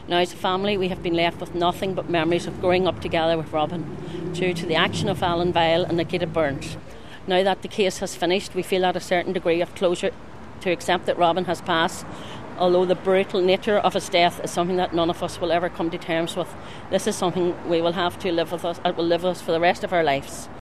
Outside court after they were sentenced